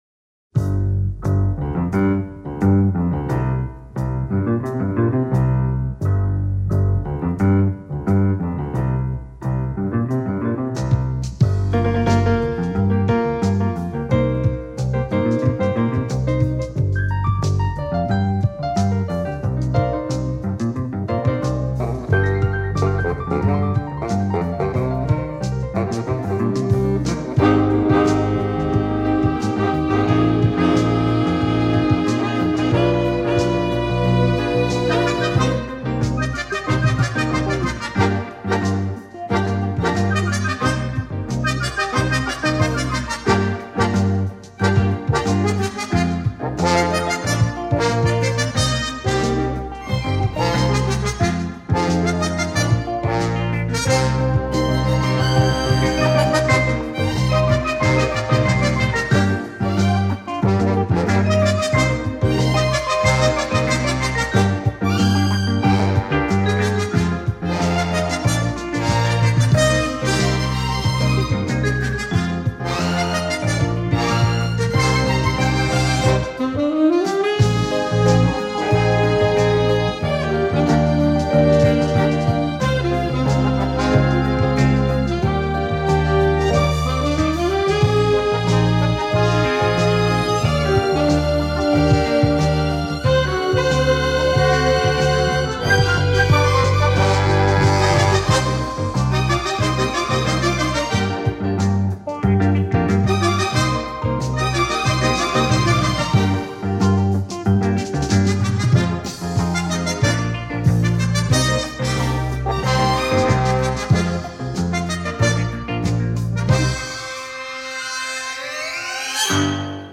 Темп и аранжировка совсем другая, но вроде ОН.